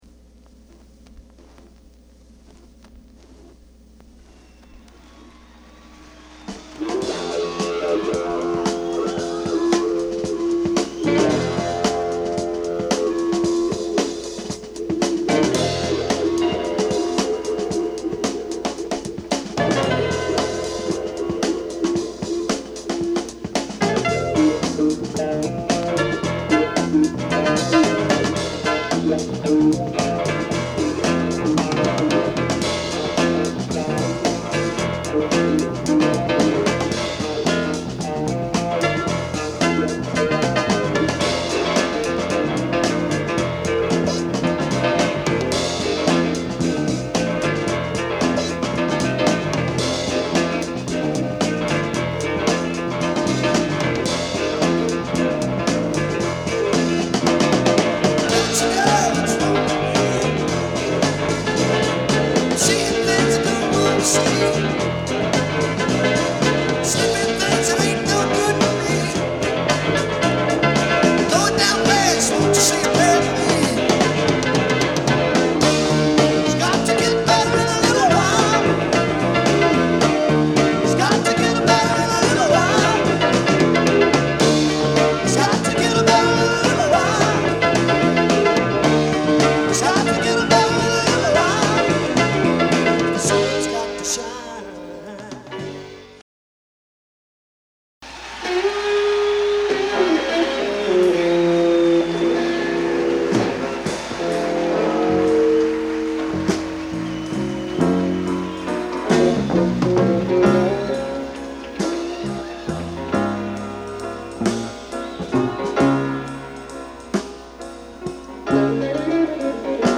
ROCK / BRITISH ROCK / BLUES
盤は薄い擦れや僅かですが音に影響がある傷がいくつかある、使用感が感じられる状態です。